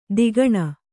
♪ digaṇa